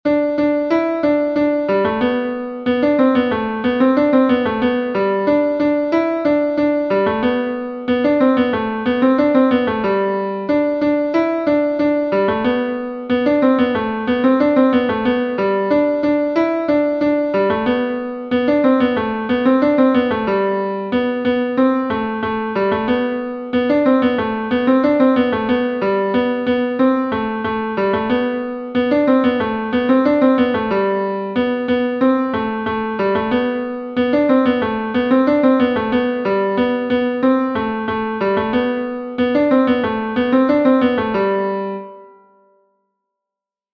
Bal d’Erquy (version midi, fichier en téléchargement ci-dessous)